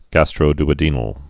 (găstrō-də-dēnəl, -dy-, -d-ŏdn-əl, -dy-)